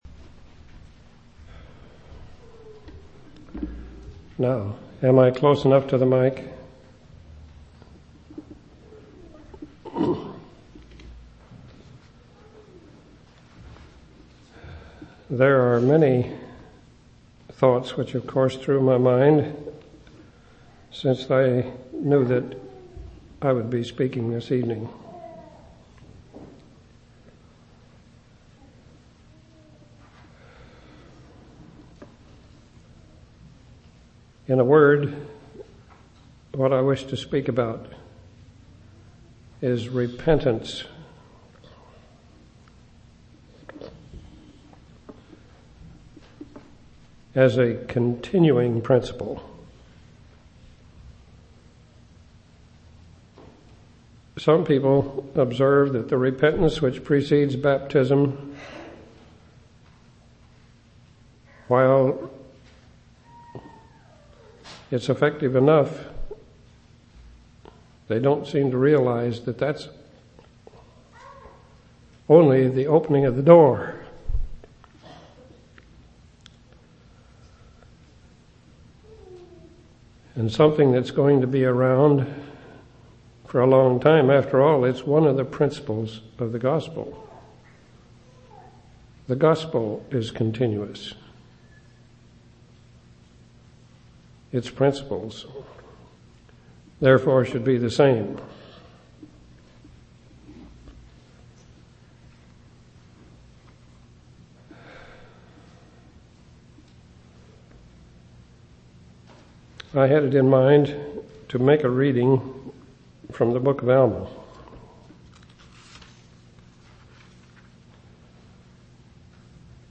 10/24/1993 Location: Temple Lot Local Event